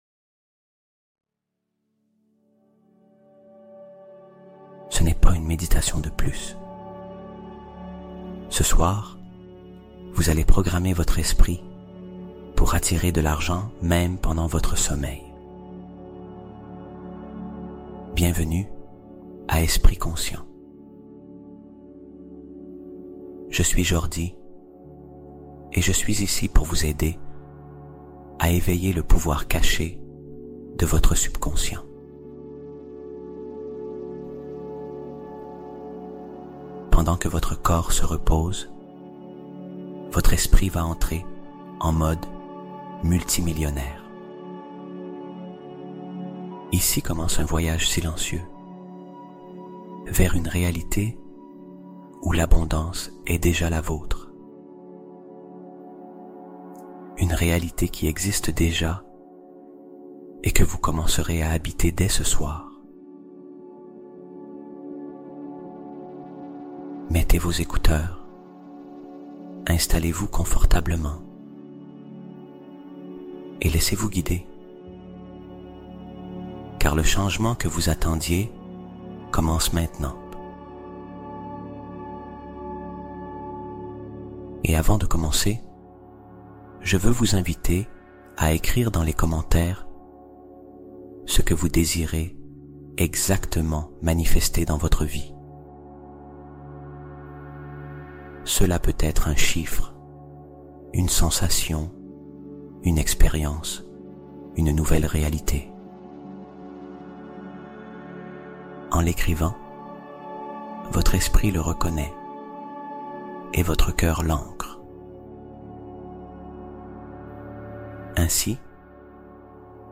Deviens Millionnaire Pendant Ton Sommeil – Ondes Theta + Messages Subliminaux Interdits